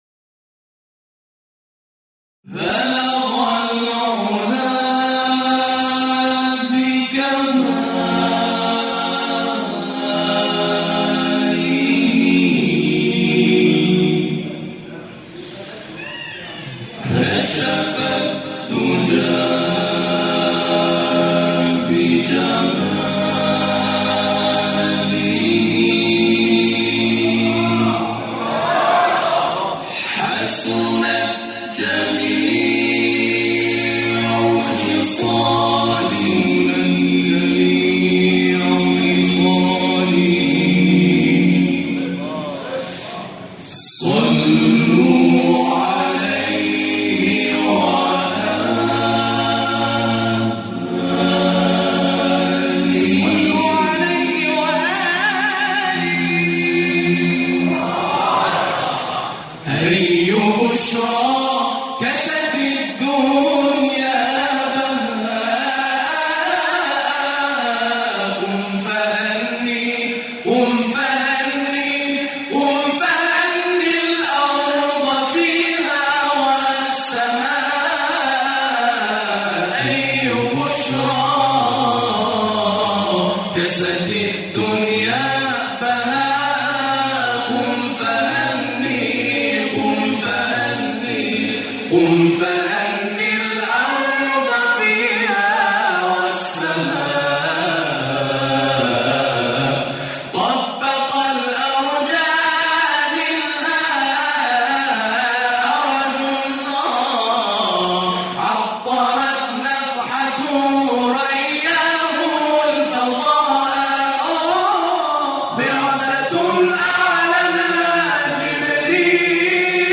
تواشیح
گروه تواشیح ثقلین یزد